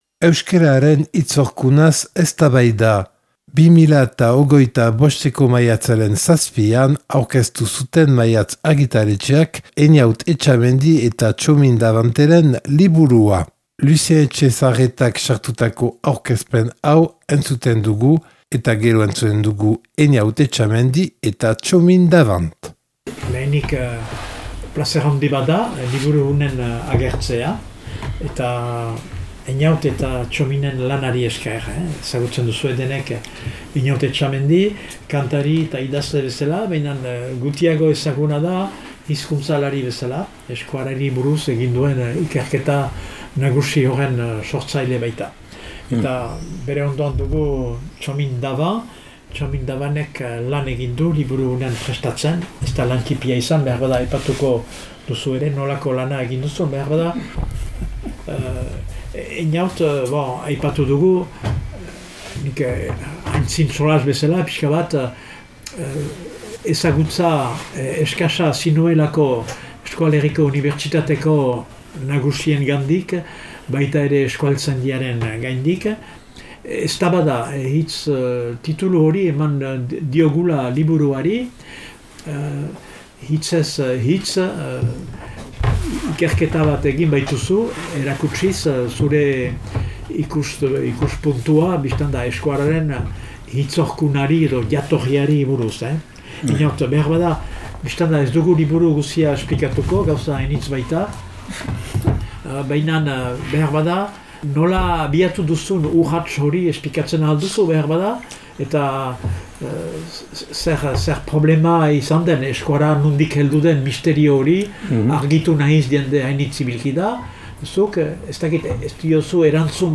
hitzaldi